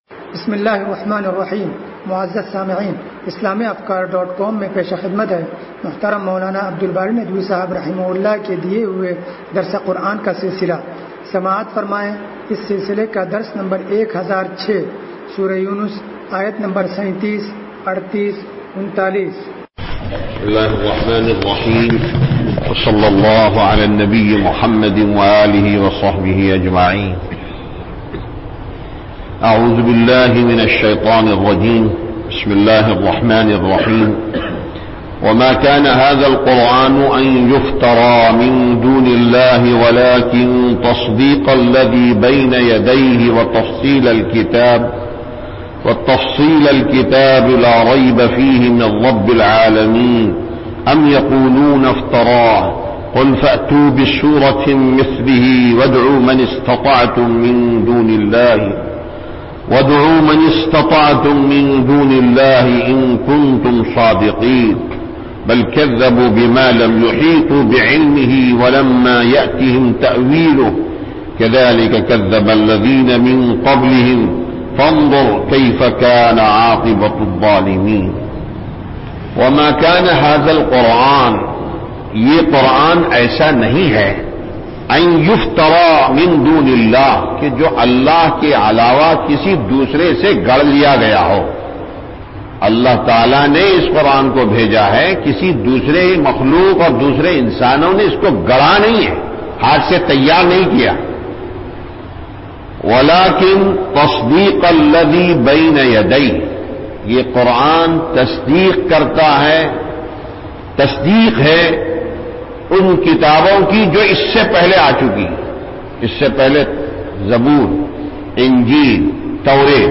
درس قرآن نمبر 1006
درس-قرآن-نمبر-1006.mp3